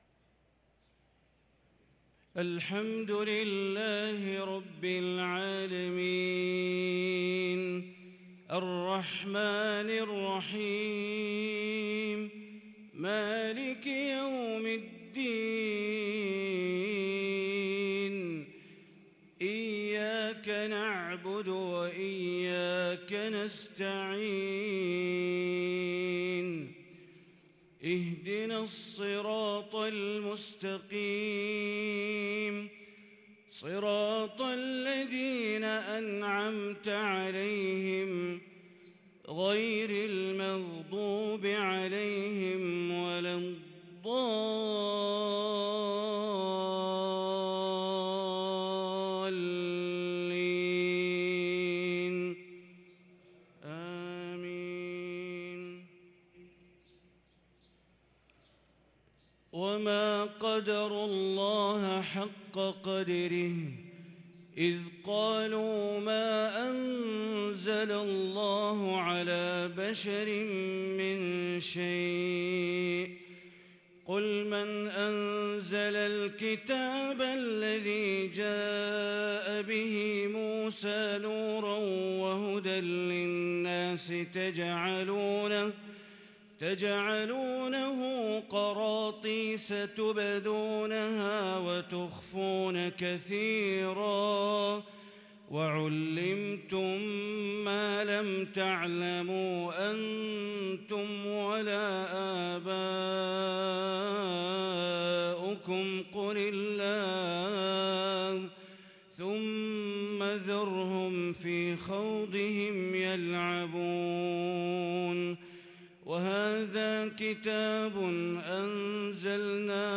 صلاة العشاء للقارئ بندر بليلة 8 جمادي الأول 1444 هـ